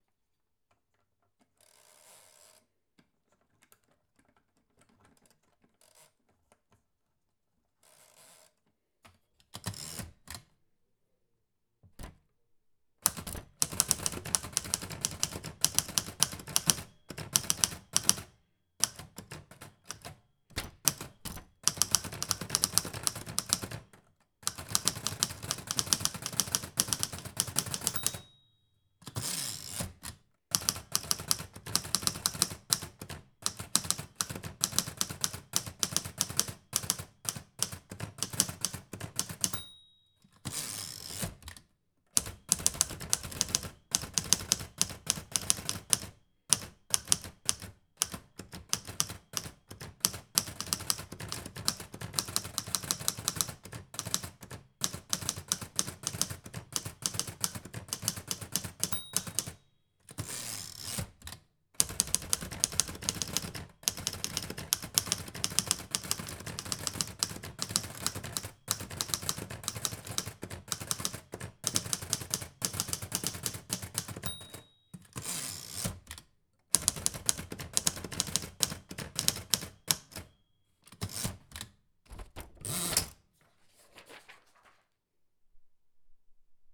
Typing on a typewriter with ding at end of each line
clicking keyboard mechanical tapping typewriter typing sound effect free sound royalty free Sound Effects